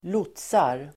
Ladda ner uttalet
Uttal: [²l'ot:sar]
lotsar.mp3